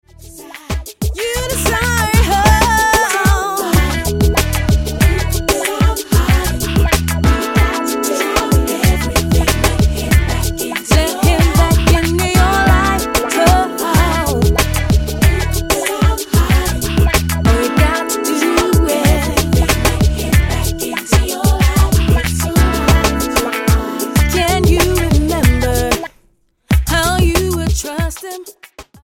British-based gospel singer
R&B